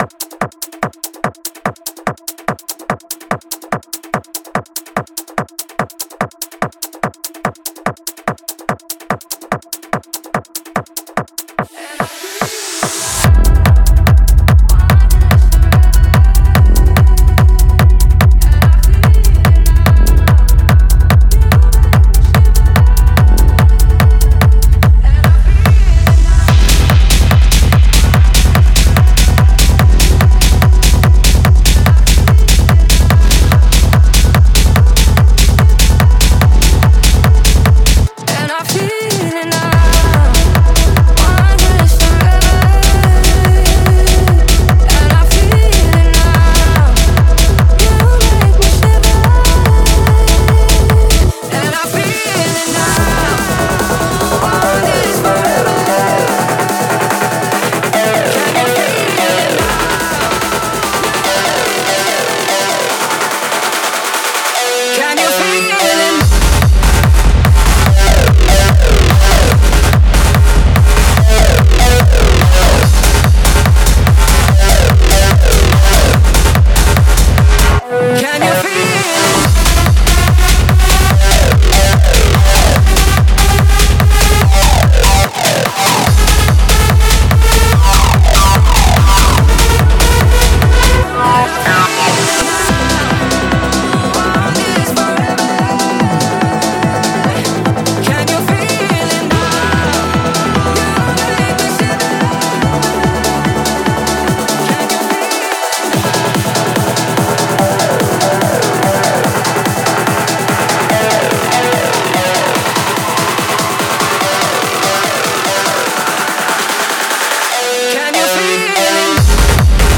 试听文件为低音质，下载后为无水印高音质文件 M币 10 超级会员 M币 6 购买下载 您当前未登录！